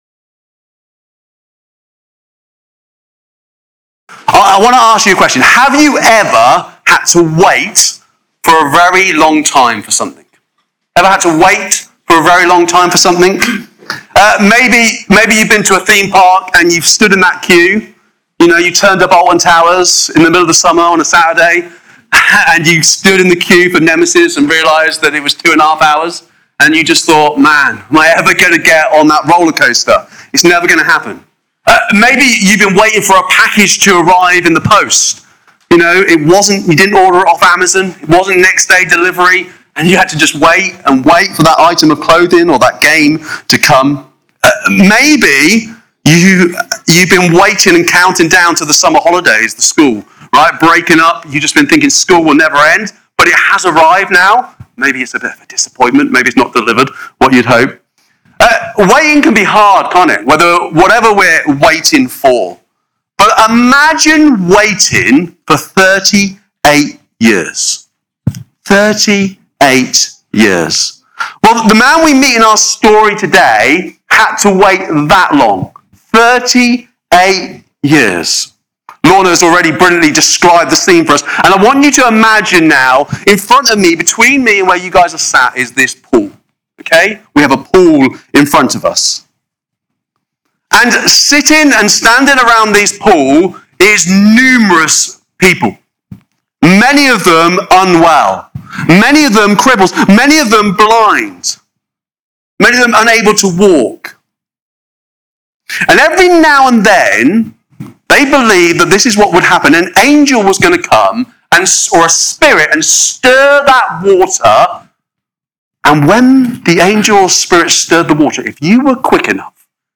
Cornerstone Wirral Sermon Podcast - Do You Believe Jesus Can Heal You?
This sermon explores Jesus’ power to restore what is broken, the deeper healing He brings, and the invitation He still extends to us today.